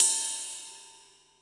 43_05_ride.wav